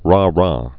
(rä)